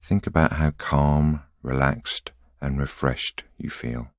a voice sample is a single voiceover statement which can be used to compose voice collections.